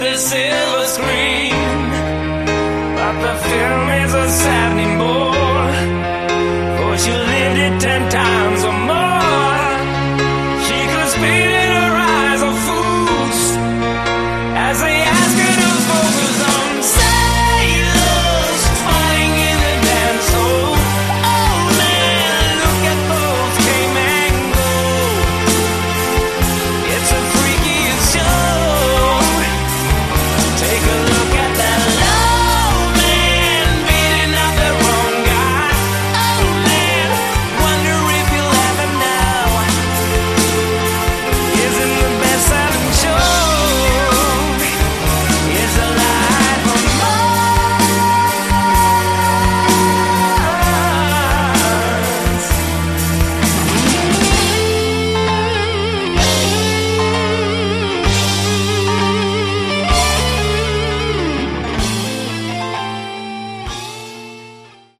Category: Melodic Hard Rock
Vocals
Guitars
Bass
Drums
Keyboards